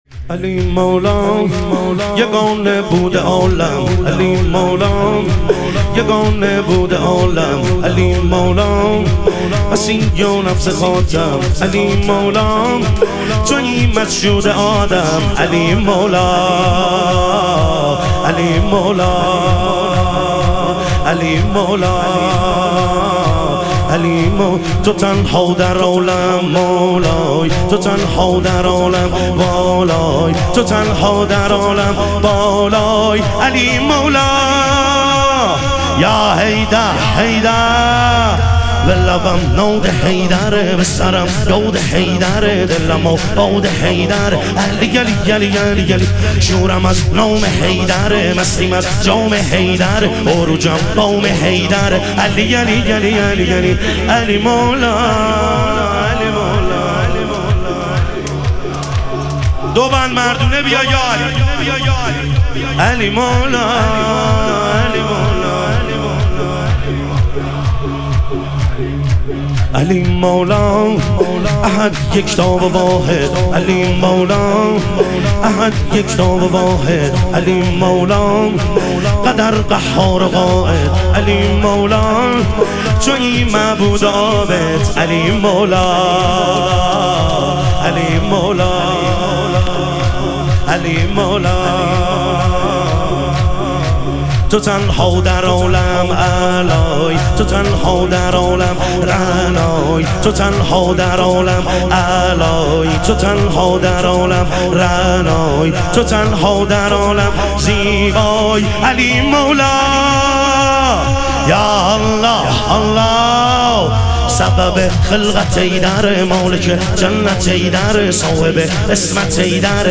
شهادت حضرت ام البنین علیها سلام - شور